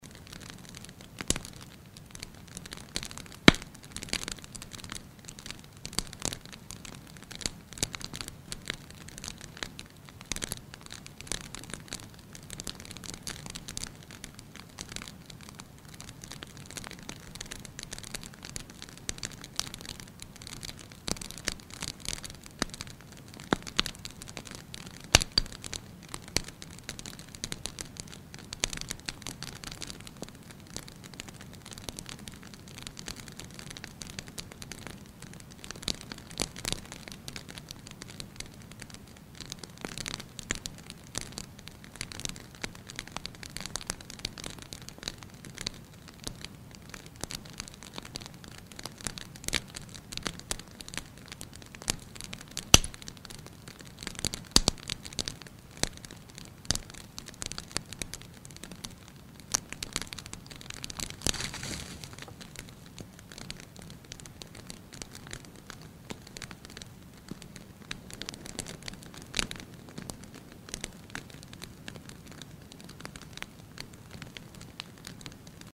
Подборка включает разные варианты: от спокойного потрескивания до яркого горения.
Спокойное горение костра с приятным треском углей